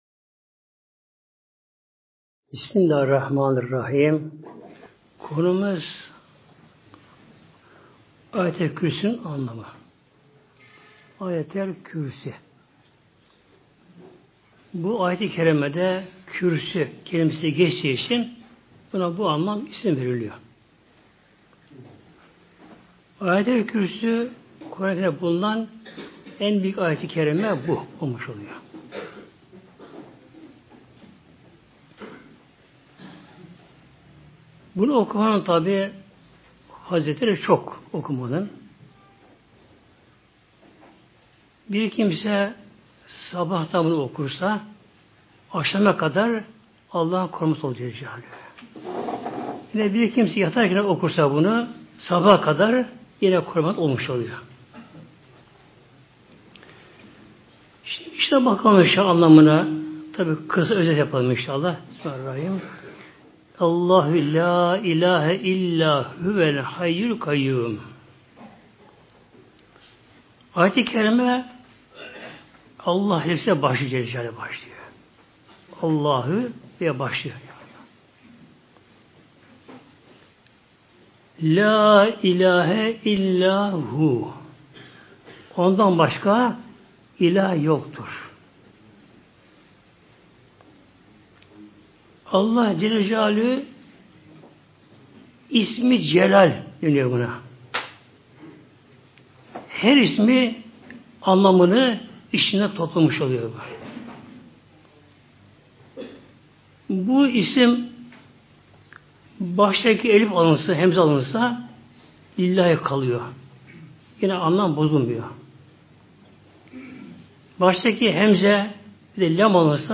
Sohbet